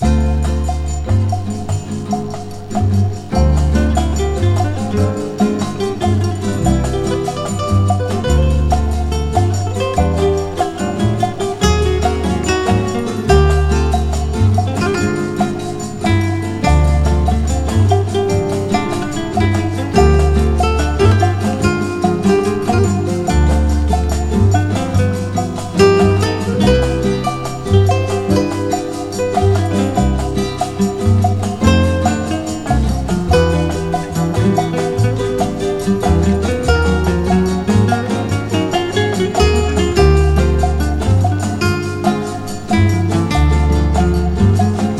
本盤もまた、多様な音使いで刺激いっぱい。
Jazz, Pop, Easy Listening, Lounge　USA　12inchレコード　33rpm　Mono